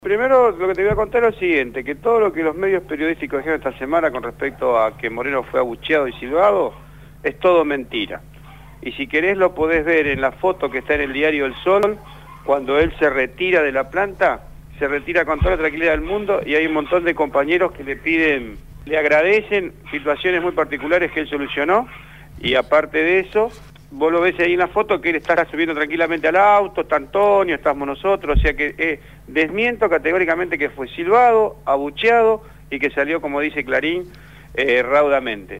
Los compañeros de «Patas Cortas» entrevistaron